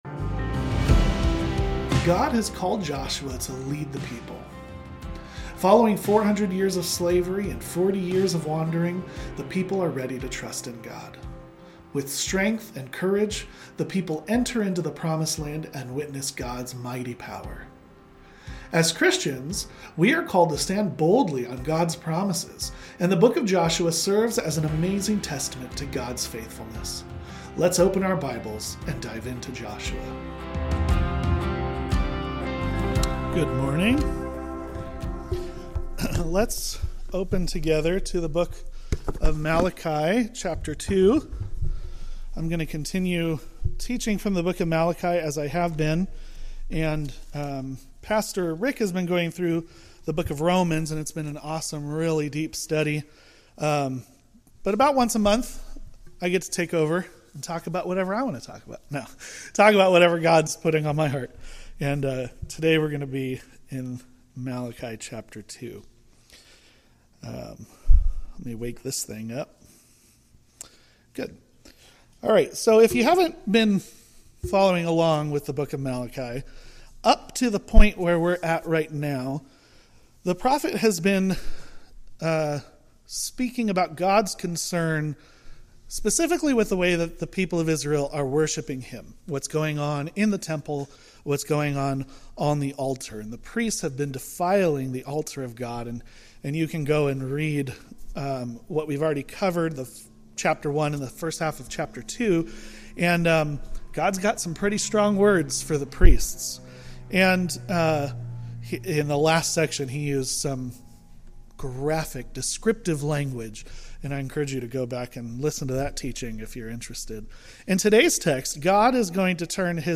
A message from the series "MALACHI: Return to Me!."